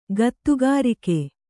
♪ gattugārike